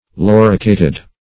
Loricated (l[o^]r"[i^]*k[=a]t`[e^]d); p. pr. & vb. n.